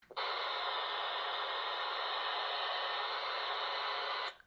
talky-walky-off.mp3